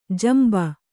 ♪ jamba